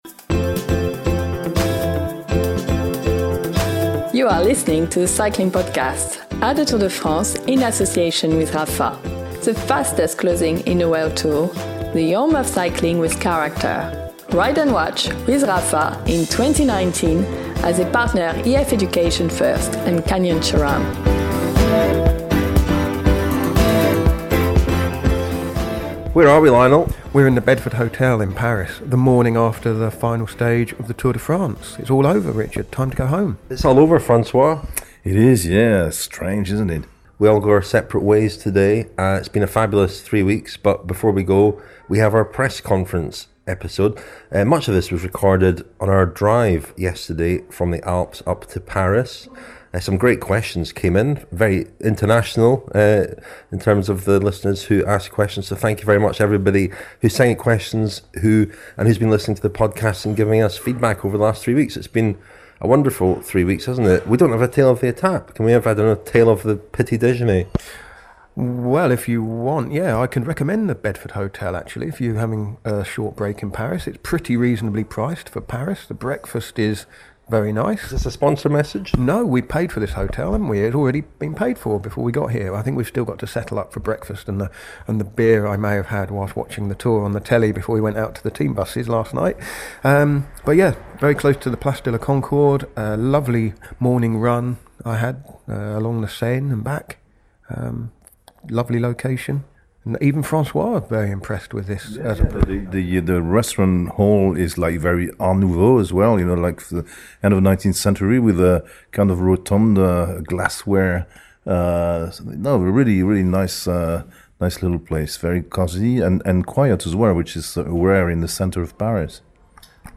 There are a few bonus interviews too.